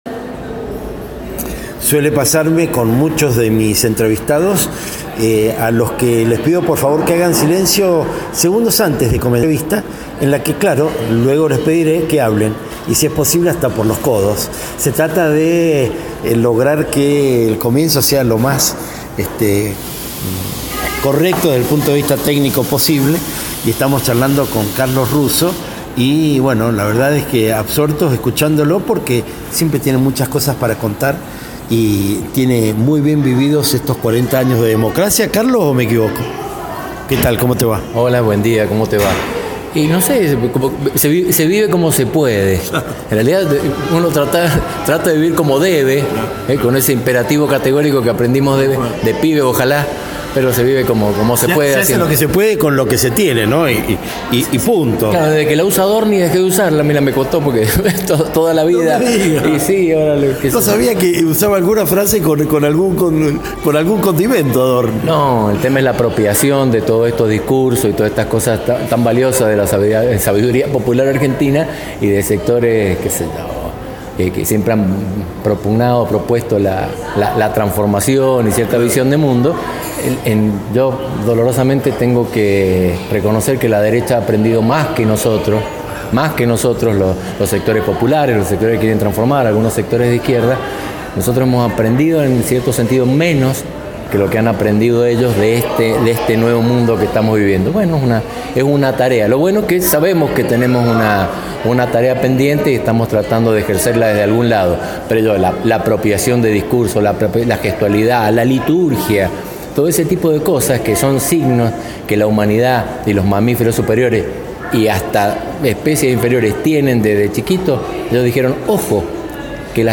Disfrutando el café, celebrando el intercambio y pasando un excelente momento en el contexto cuidado del bar de Avenida San Martín 891, de Ciudad, todo fue en el sentido esperado.
Entrevista